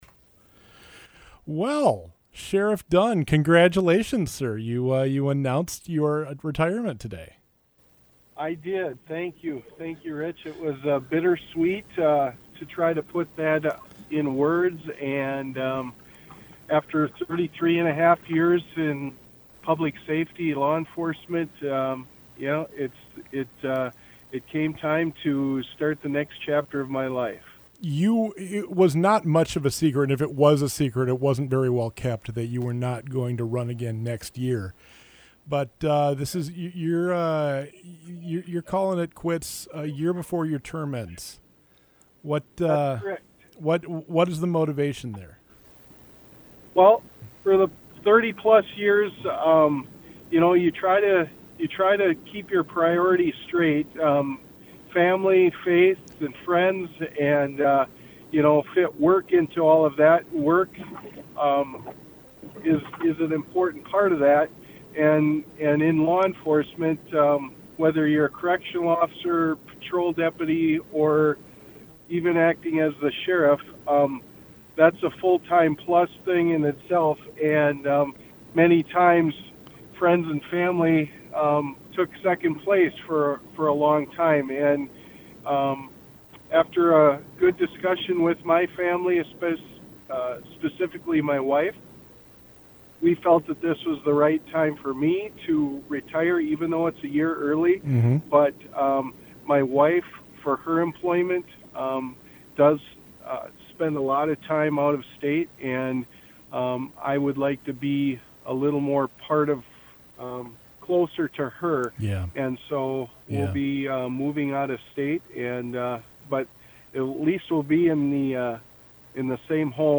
conversation with KYMN radio, the sheriff reflected on his time as sheriff, his values, his plans for the future and his concerns for the future of law enforcement.